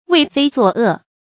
为非作恶 wéi fēi zuò è 成语解释 见“为非作歹”。